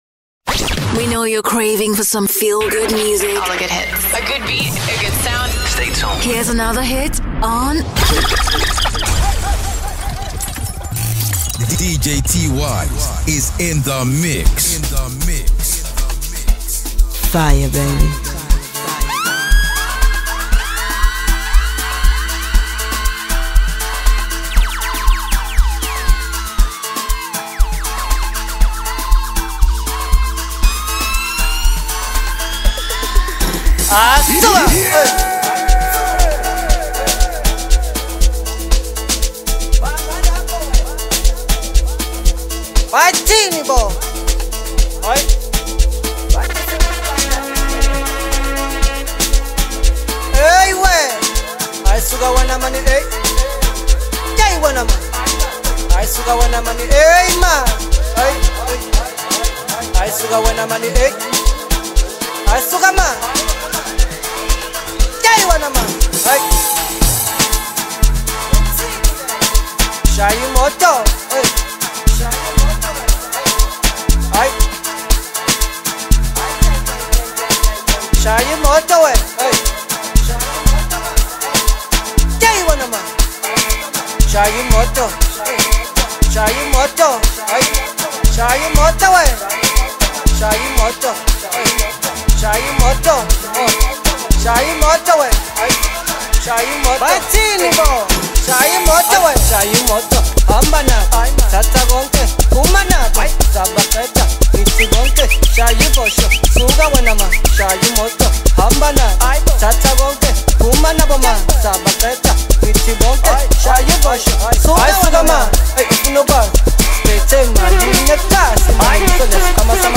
Talented Nigerian disc jockey